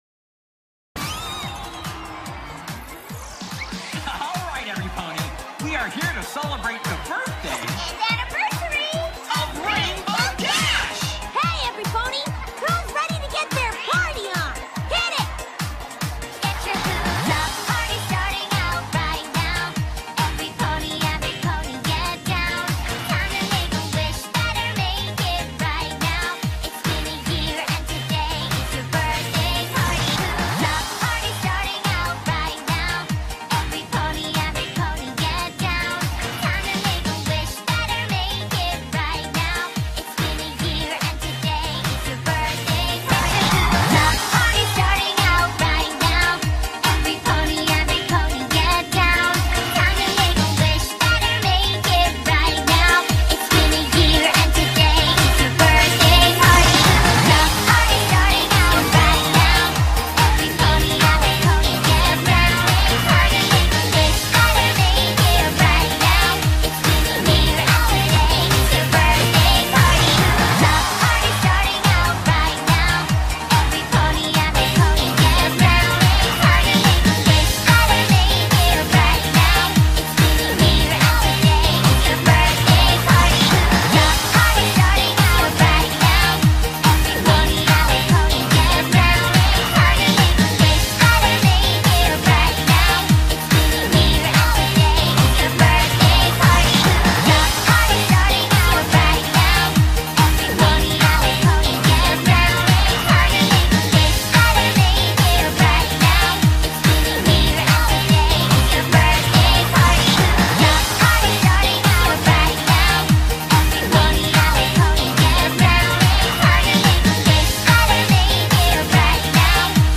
Probably the first remix of this song.